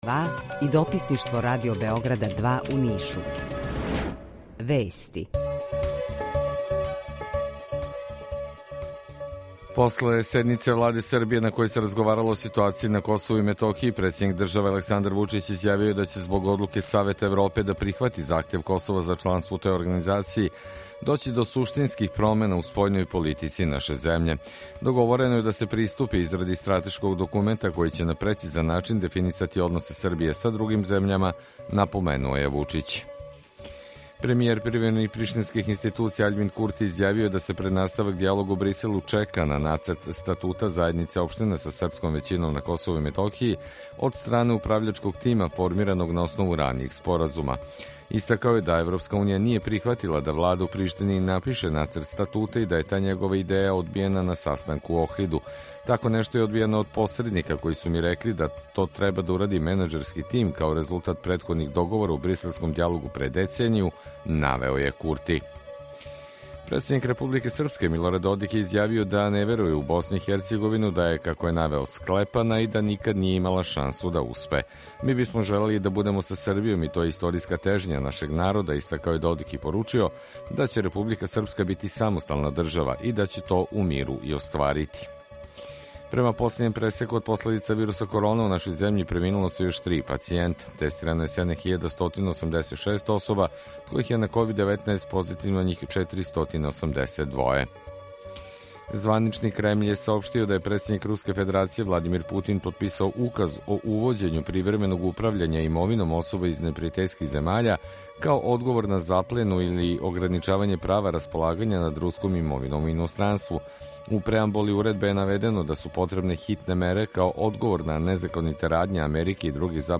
Извештај из Грачанице.
У два сата, ту је и добра музика, другачија у односу на остале радио-станице.